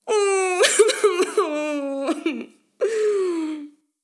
Llanto de mujer corto
llanto
mujer
Sonidos: Acciones humanas
Sonidos: Voz humana